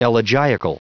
Prononciation du mot elegiacal en anglais (fichier audio)
elegiacal.wav